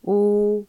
雅文檢測音
u_-15db.mp3